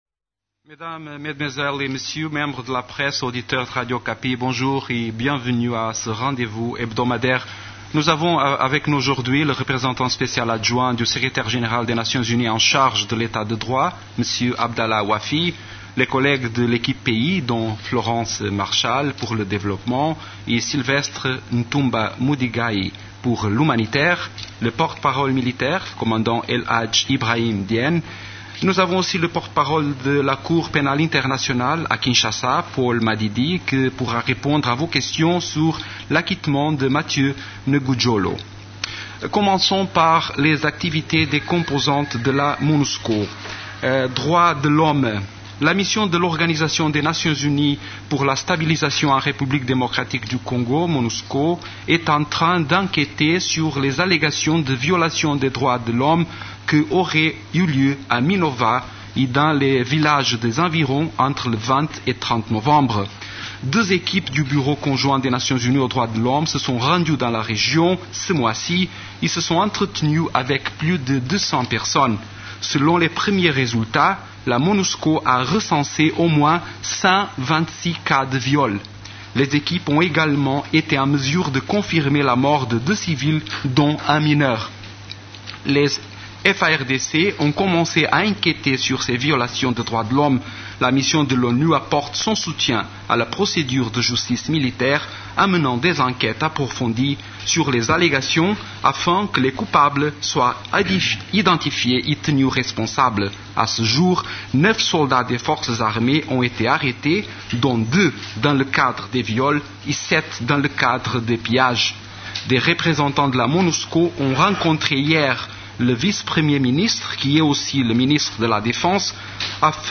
Conférence du 19 décembre 2012
La conférence hebdomadaire des Nations unies du mercredi 19 décembre a porté sur les sujets suivants:
Télécharger Vous pouvez aussi écouter la série de questions-réponses avec les journalistes: Fichier audio : téléchargez Flash pour écouter.